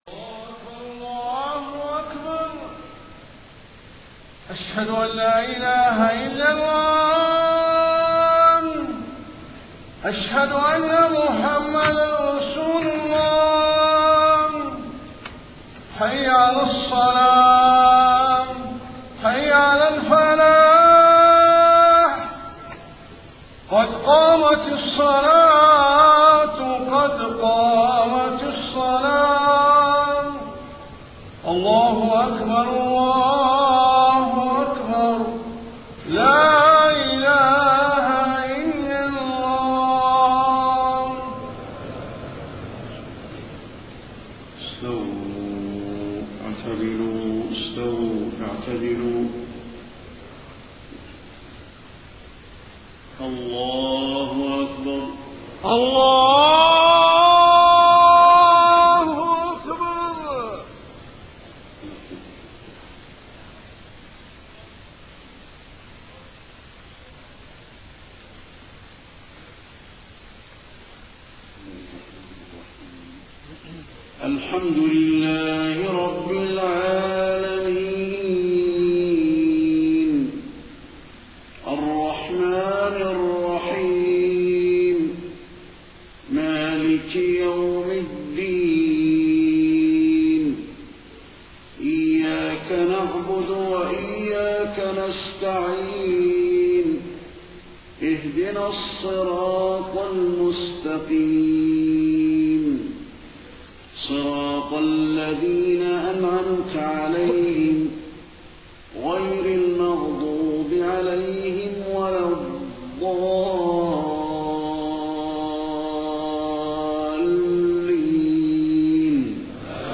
صلاة العشاء 23 محرم 1431هـ خواتيم سورة الرحمن 46-78 > 1431 🕌 > الفروض - تلاوات الحرمين